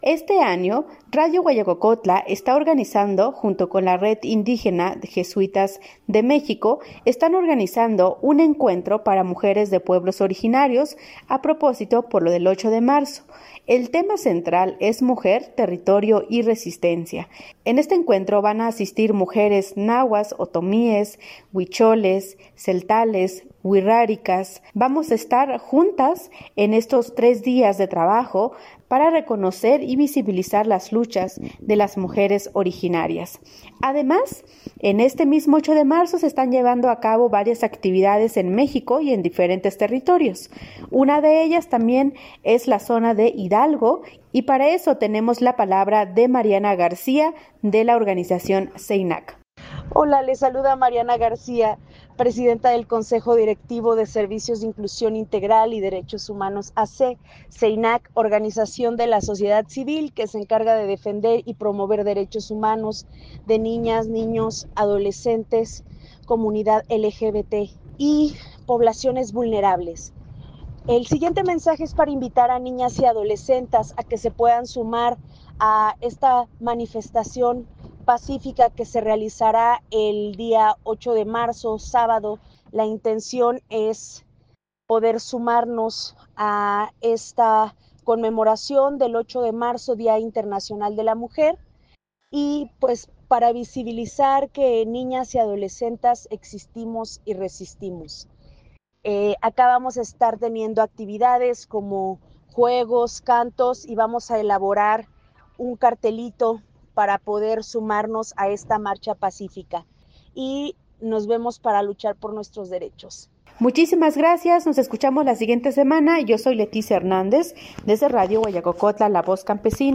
este reporte